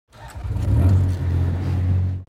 دانلود آهنگ تراکتور 6 از افکت صوتی حمل و نقل
دانلود صدای تراکتور 6 از ساعد نیوز با لینک مستقیم و کیفیت بالا
جلوه های صوتی